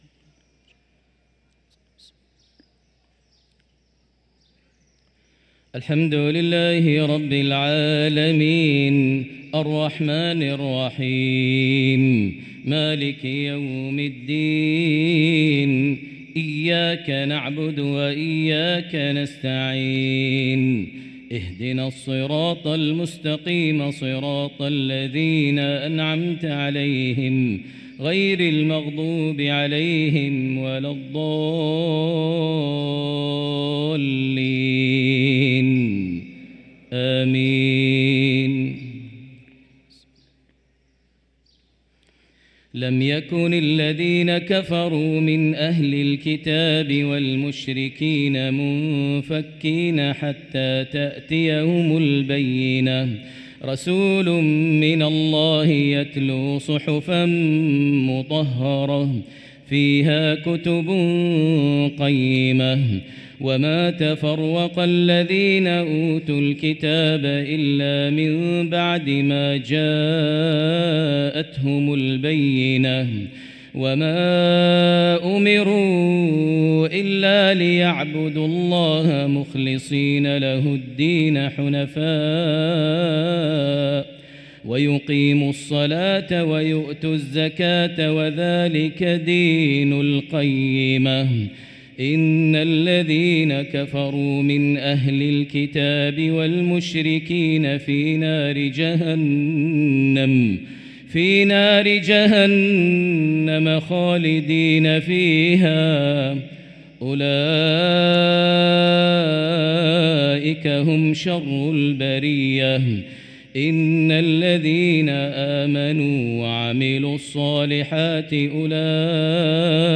صلاة المغرب للقارئ ماهر المعيقلي 8 رجب 1445 هـ